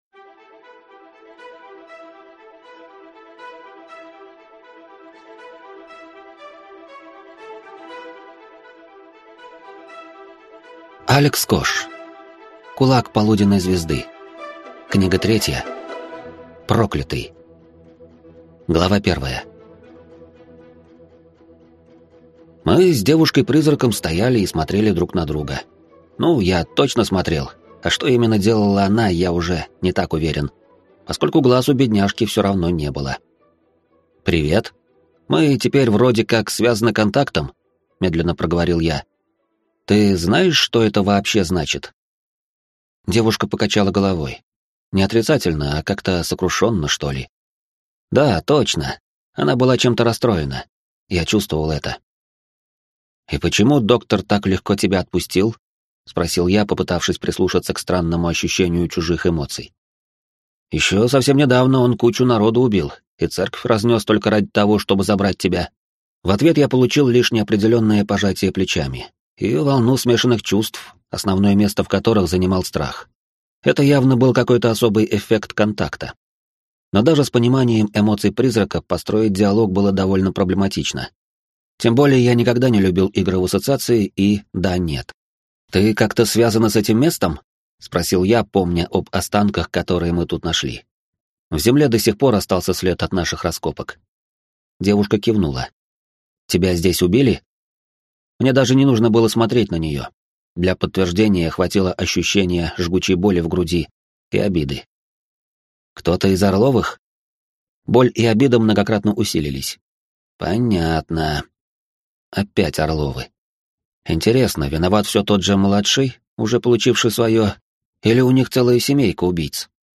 Аудиокнига Кулак Полуденной Звезды. Книга 3. Проклятый | Библиотека аудиокниг